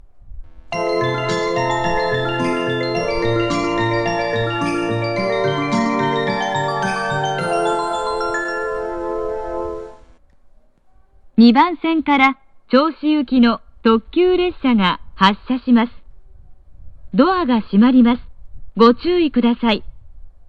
発車メロディー
･音質：良